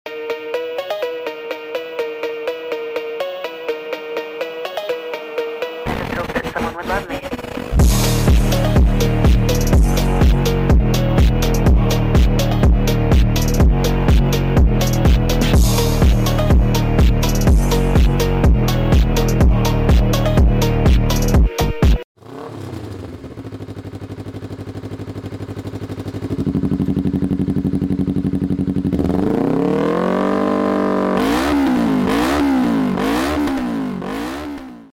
gass Tipis Tridente Scorpion CBR250Rr